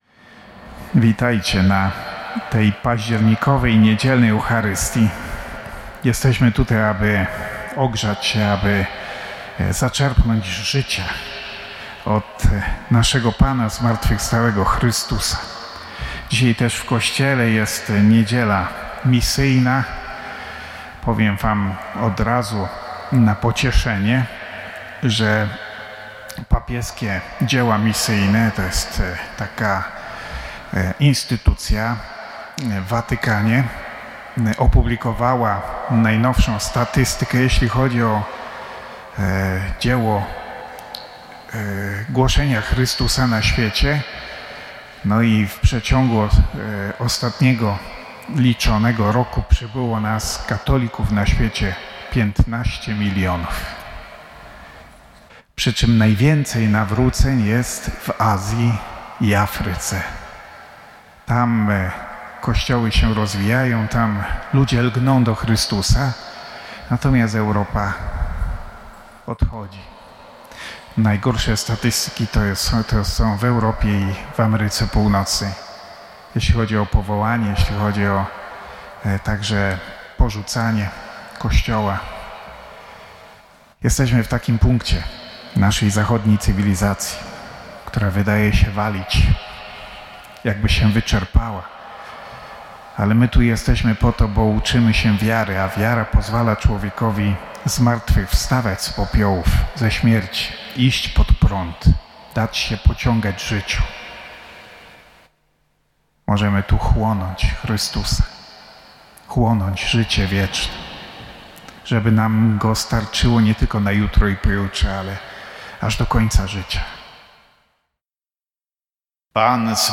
Kazania